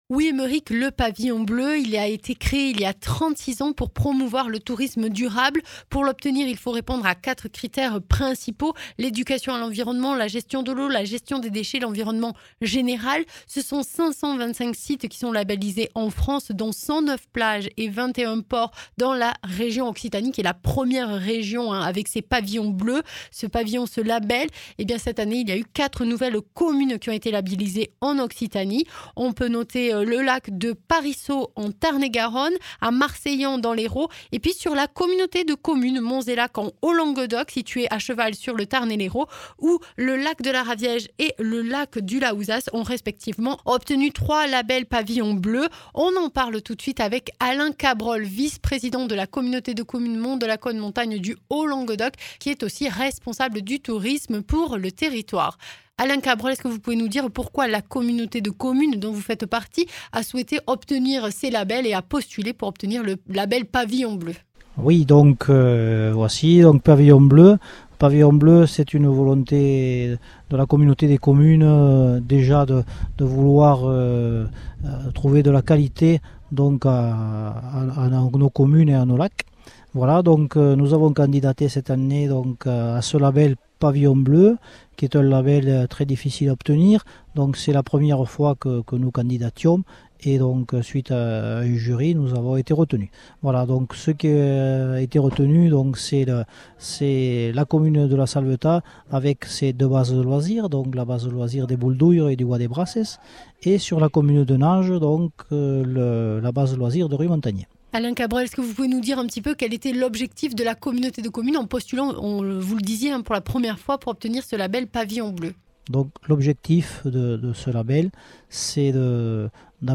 Interviews
Invité(s) : Alain Cabrol, vice-président de la Communauté de Communes Monts de Lacaune/Montagne du Haut Languedoc (Tarn & Hérault) en charge du tourisme